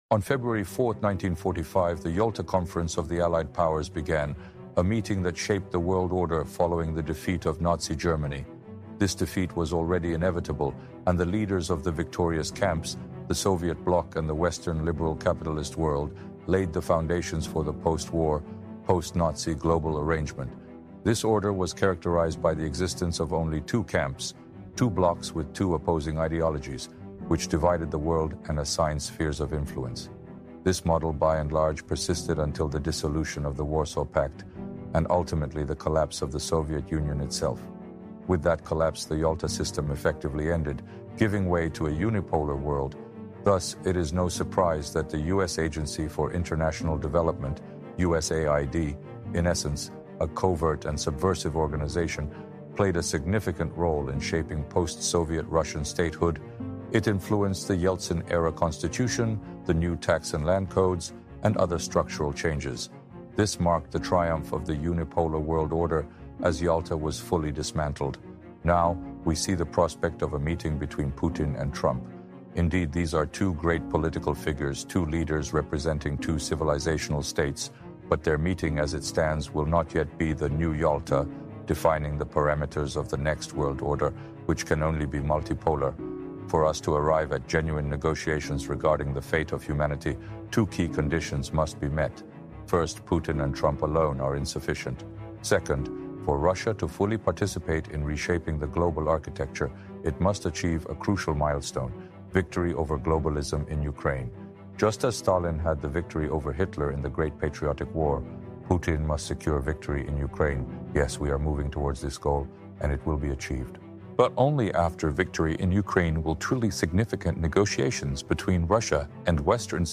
Just as the Soviet Union’s victory over Nazi Germany paved the way for the Cold War bipolar world system, Russia’s coming victory over NATO in Ukraine will usher in a new multipolar world order, AI Alexander Dugin explains.